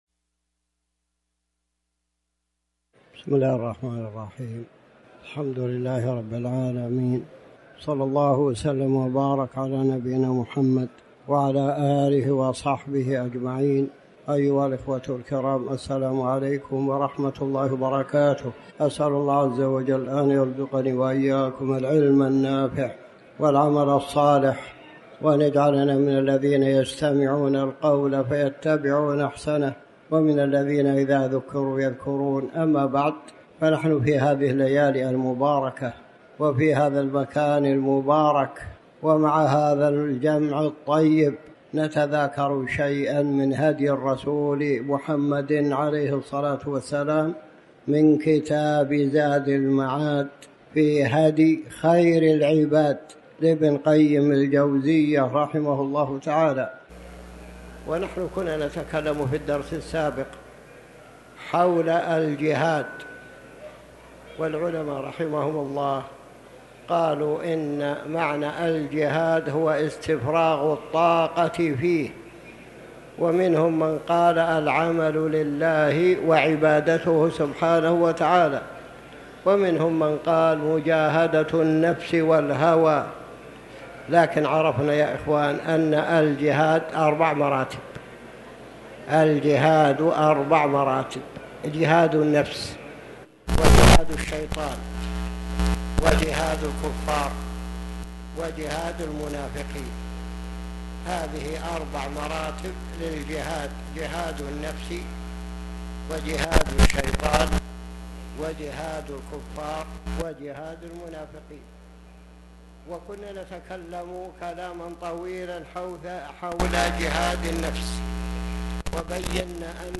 تاريخ النشر ٢٣ ذو الحجة ١٤٤٠ هـ المكان: المسجد الحرام الشيخ